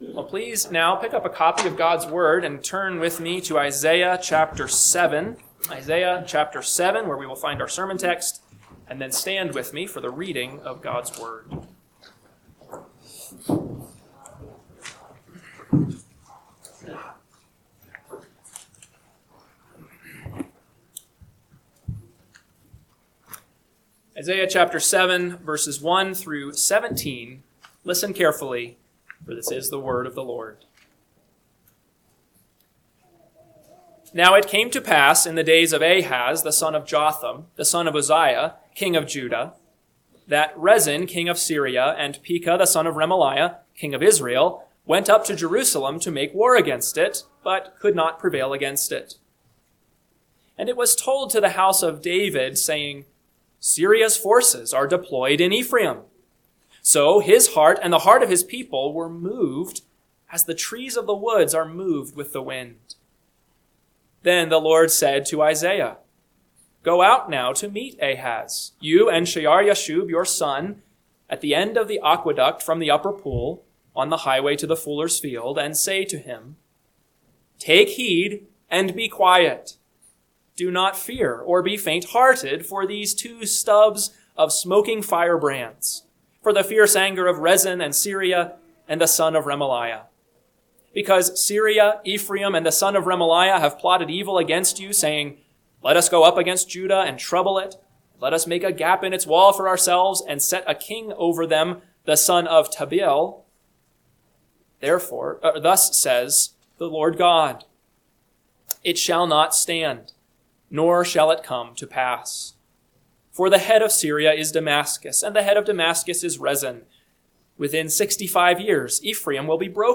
AM Sermon – 12/21/2025 – Isaiah 7:1-7 – Northwoods Sermons